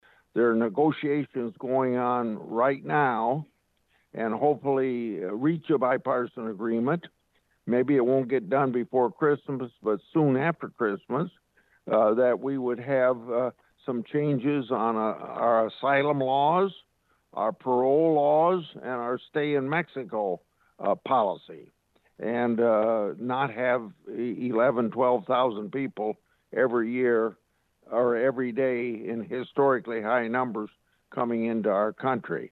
(Washington D.C.) Iowa Senator Chuck Grassley, appearing on his weekly Capitol Hill report, responded to a question about a quota at the border.